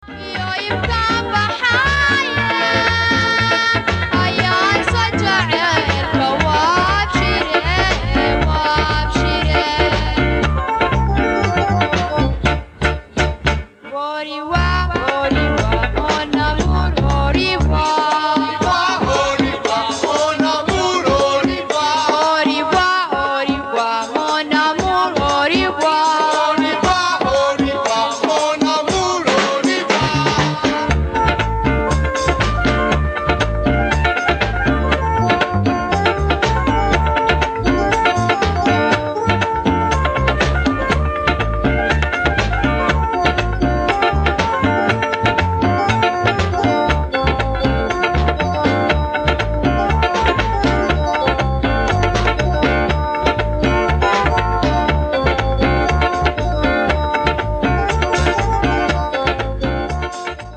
Digitized from cassettes